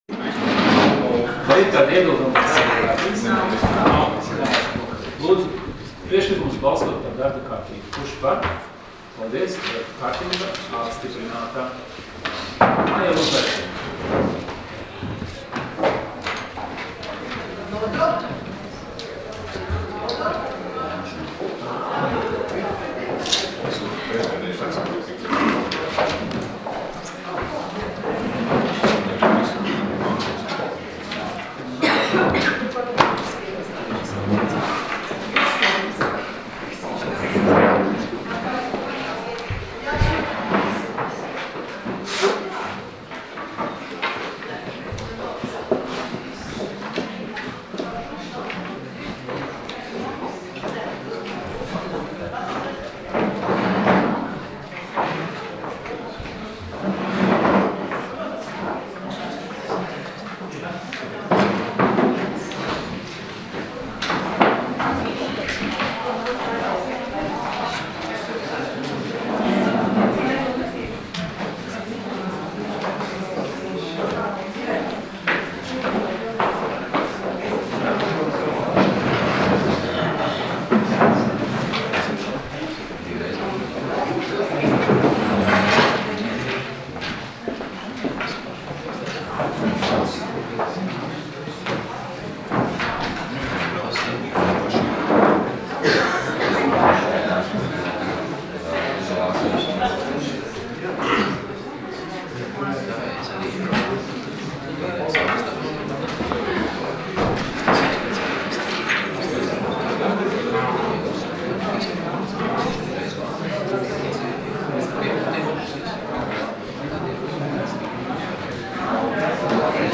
Domes sēdes 15.01.2016. audioieraksts